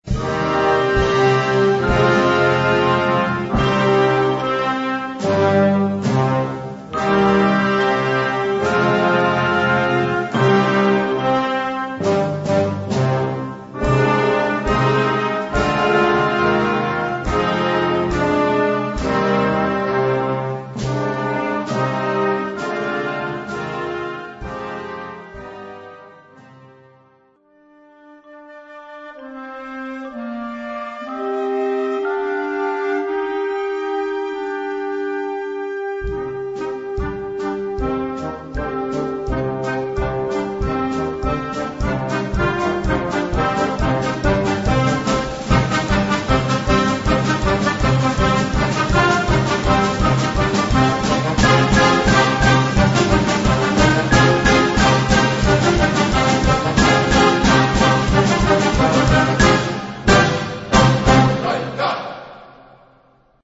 Categorie Harmonie/Fanfare/Brass-orkest
Subcategorie Concertmuziek
Bezetting Ha (harmonieorkest); YB (jeugdorkest)